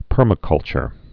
(pûrmə-kŭlchər)